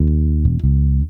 Bass_08.wav